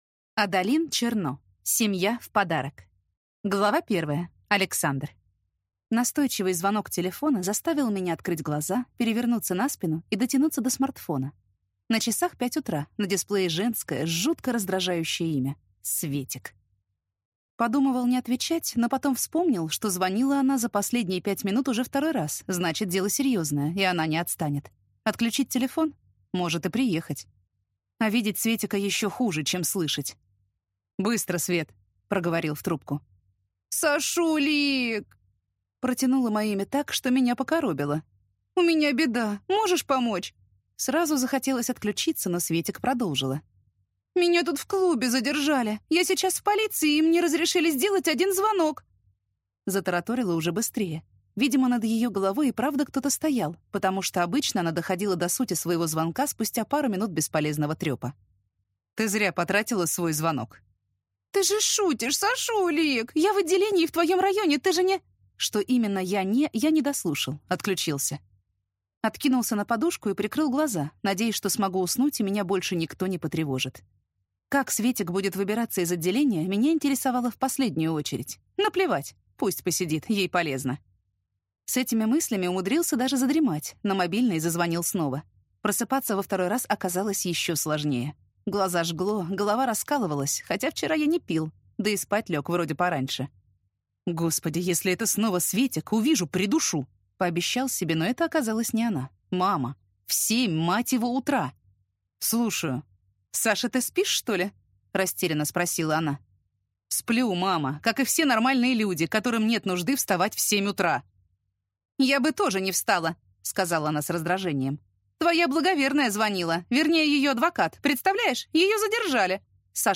Аудиокнига Семья в подарок | Библиотека аудиокниг